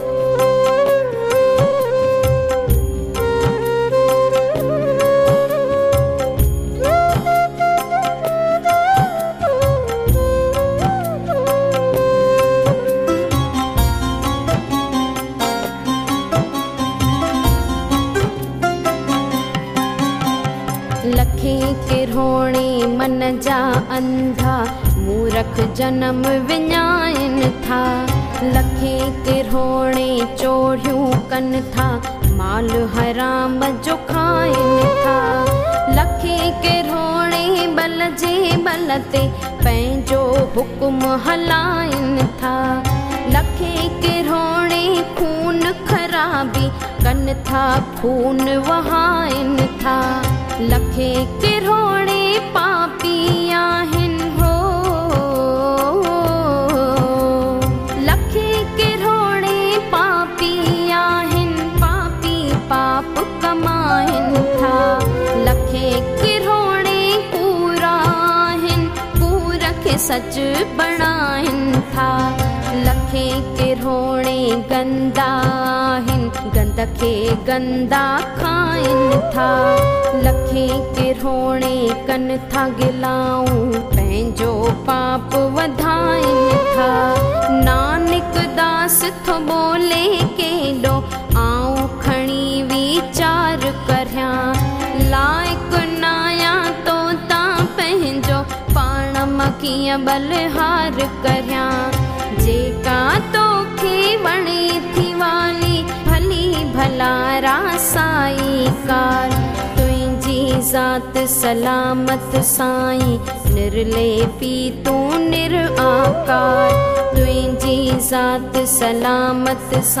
Melodious Voice